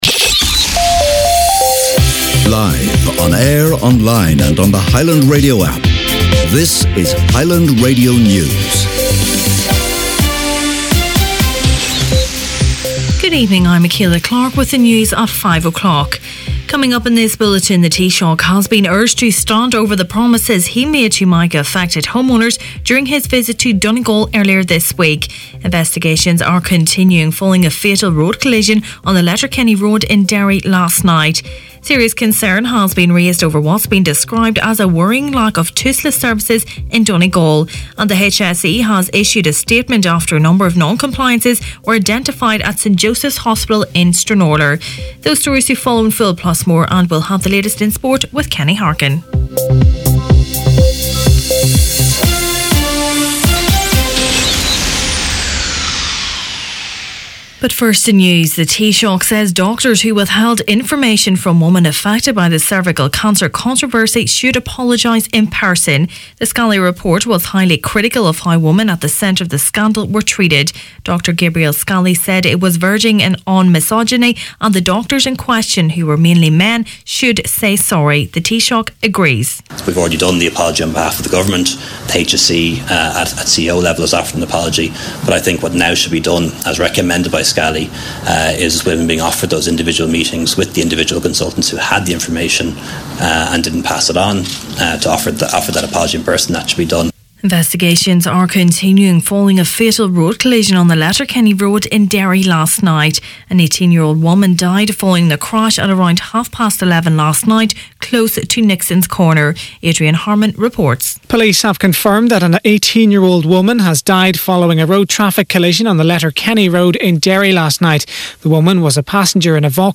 Main Evening News, Sport and Obituaries Thursday September 13th